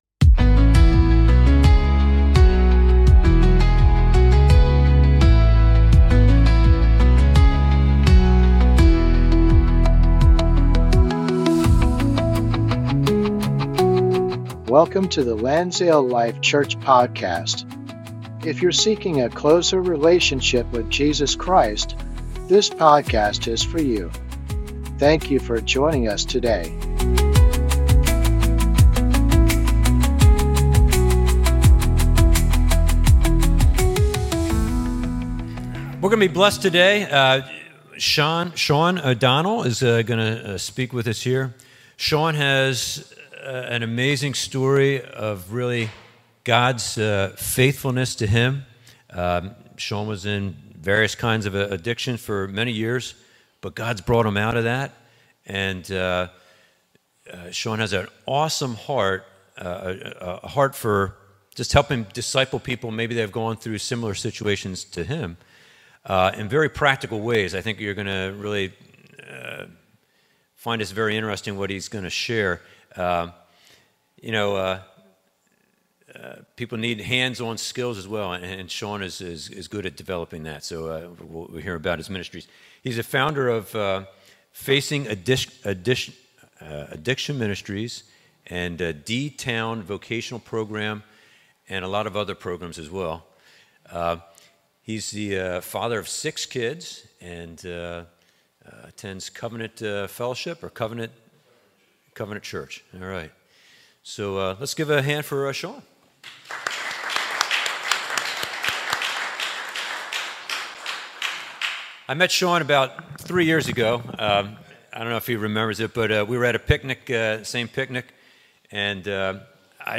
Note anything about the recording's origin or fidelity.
Men's Breakfast - 2025-08-30